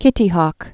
(kĭtē hôk)